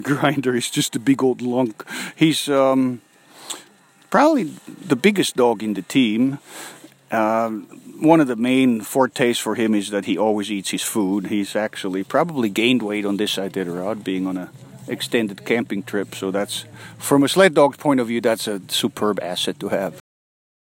Current Location: WHITE MOUNTAIN, ALASKA
Temperature: 24F / OUTDOORS